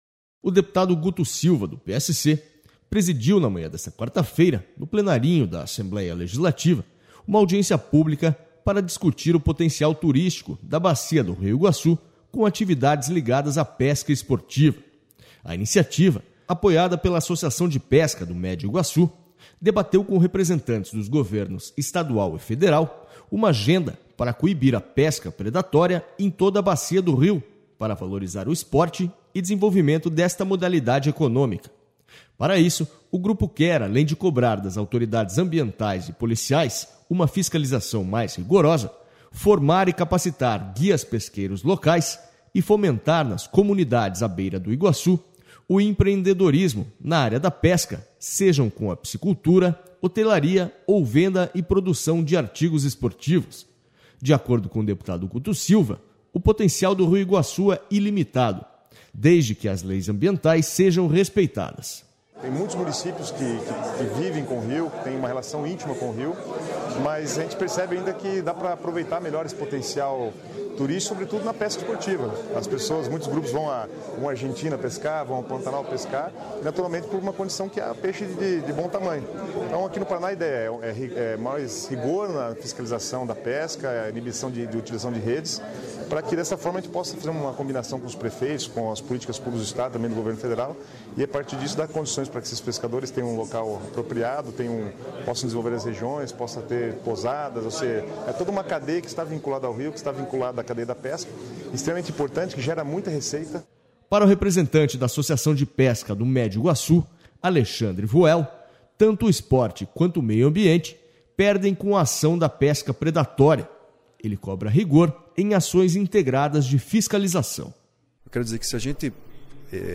Audiência debate pesca predatória versus pesca esportiva no Iguaçu
O deputado Guto Silva, do PSC, presidiu na manhã desta quarta-feira, no plenarinho da Assembleia Legislativa, uma audiência pública para discutir o potencial turístico da bacia do Rio Iguaçu com atividades ligadas à pesca esportiva.//A iniciativa, apoiada pela Associação de Pesca do Médio Iguaçu, de...